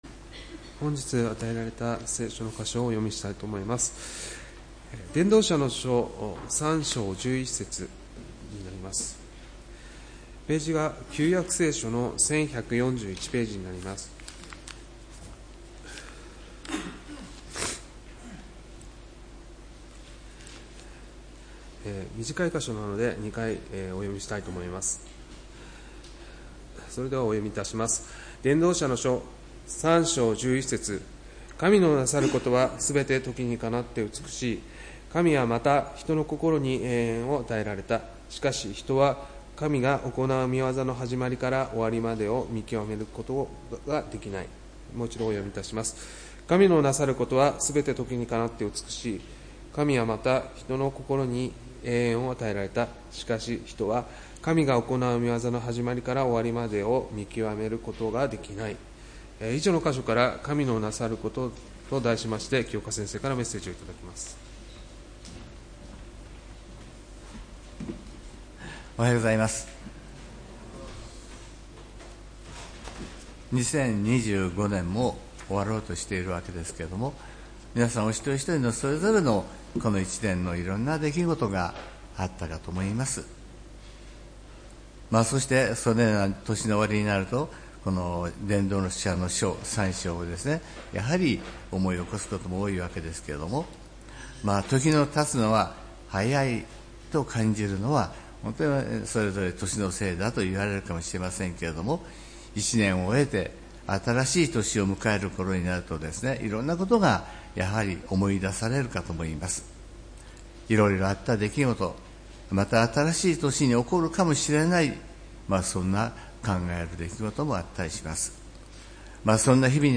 礼拝メッセージ「神のなさること」（12月28日）